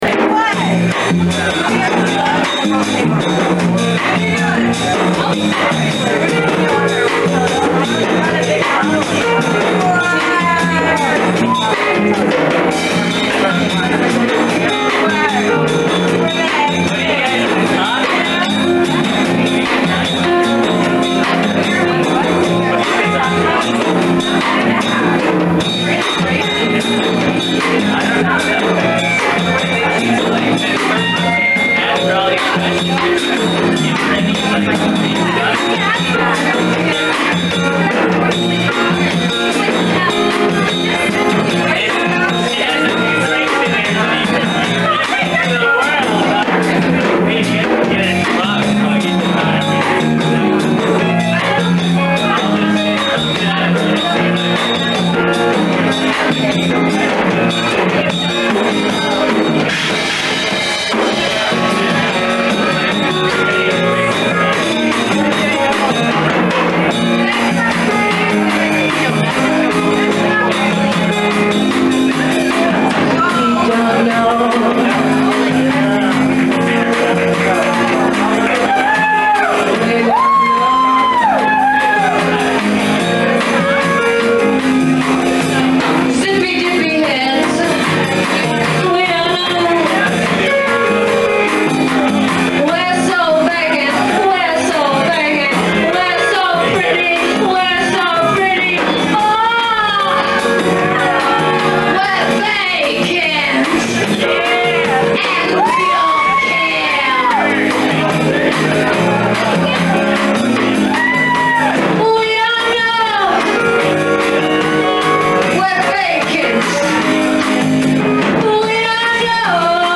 Keyboard
Bass
Drums
Live at the Lizard Lounge Cambridge MA 1998